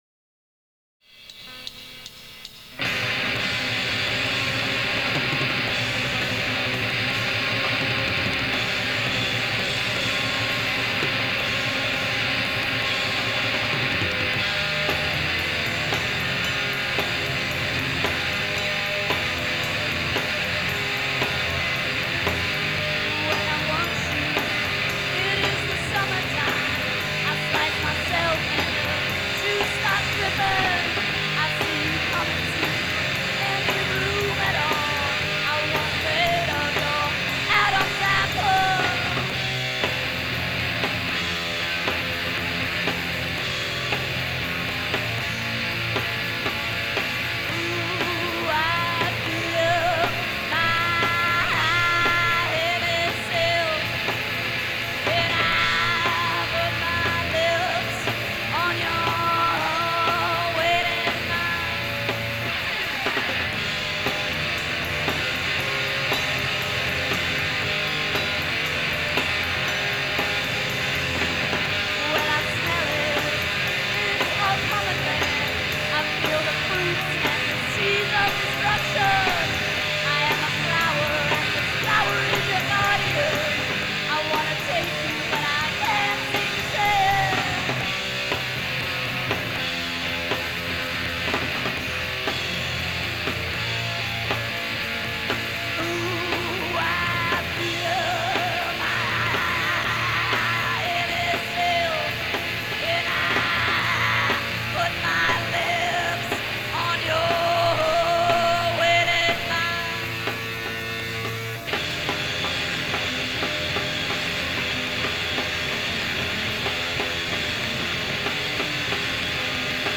powerful vocals
loud guitar